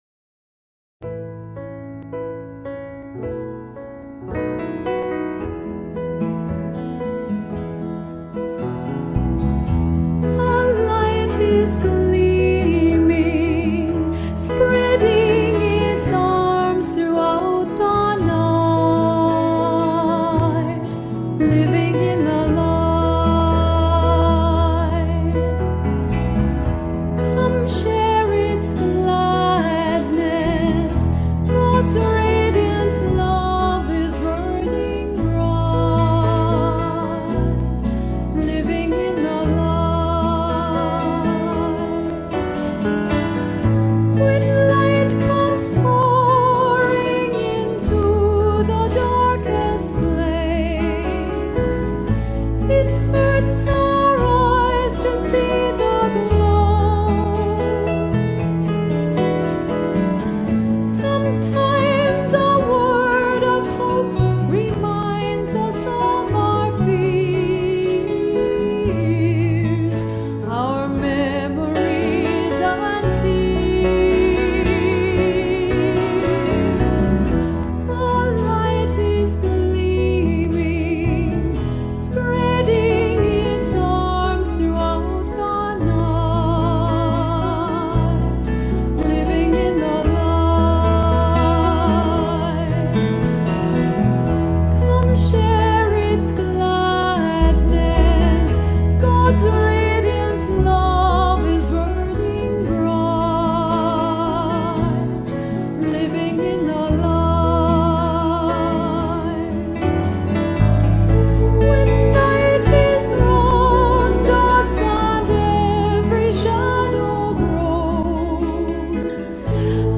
Welcome to this time of worship.
Following the blessing you are again invited to join in the singing of either a contemporary song or a traditional hymn.